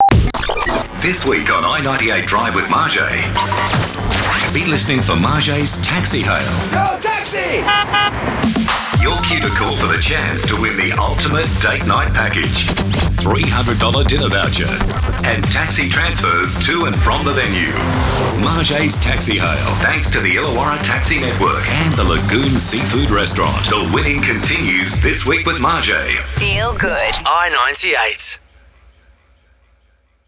Illawarra Taxi Network Radio Advertisement for the region